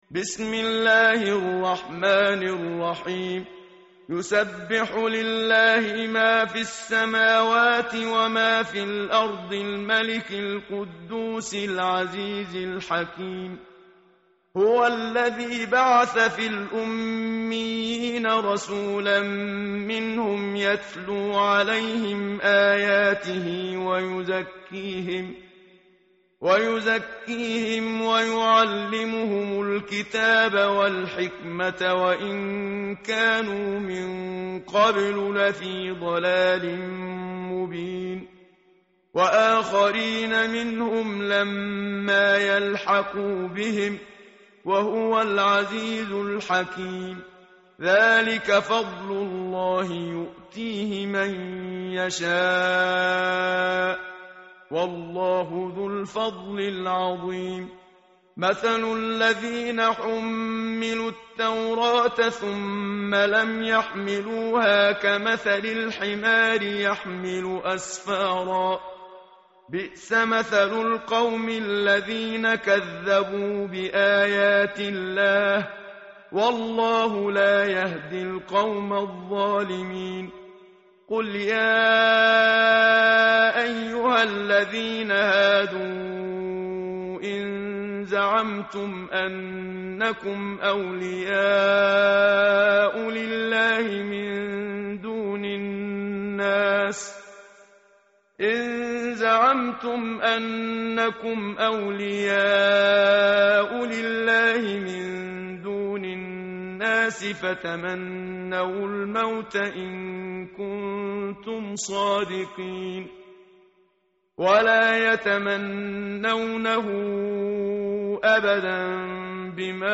tartil_menshavi_page_553.mp3